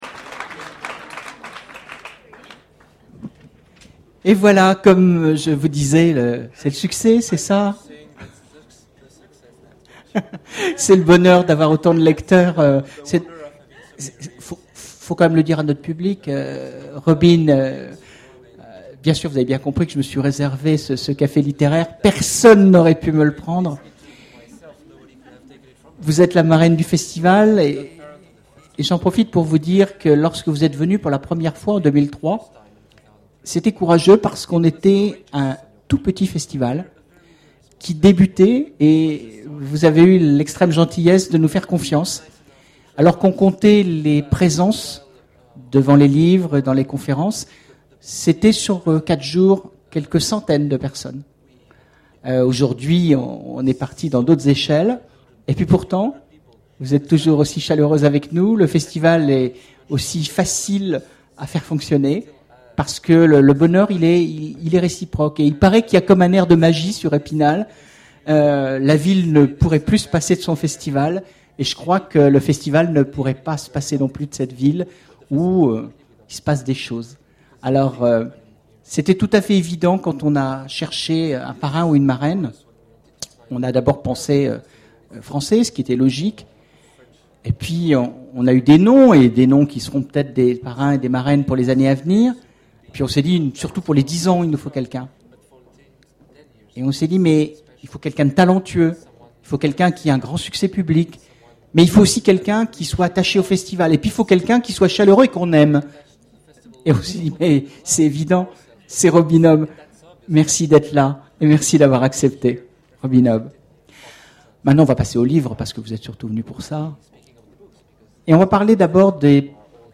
Imaginales 2011 : Conférence rencontre avec Robin Hobb
Imaginales 2011 : Conférence rencontre avec Robin Hobb Voici l'enregistrement de la rencontre avec Robin Hobb. Télécharger le MP3 à lire aussi Robin Hobb Genres / Mots-clés Rencontre avec un auteur Conférence Partager cet article